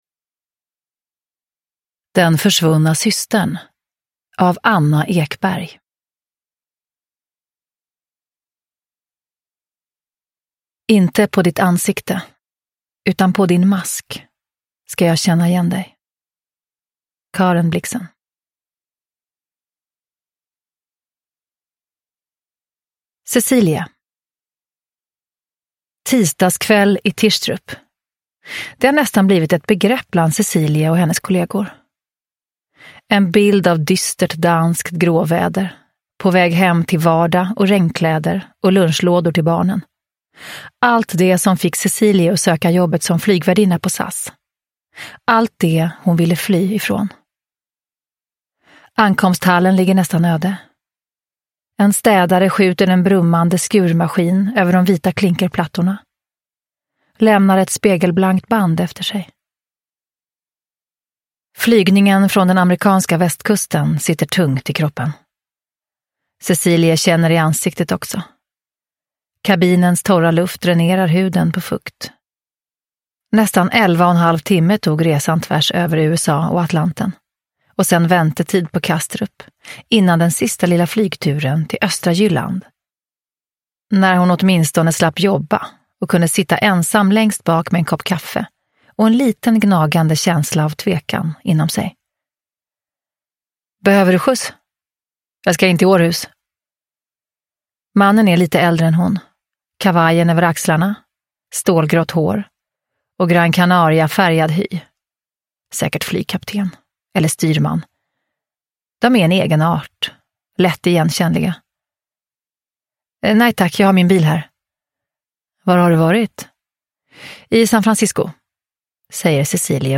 Den försvunna systern – Ljudbok – Laddas ner
Uppläsare: Eva Röse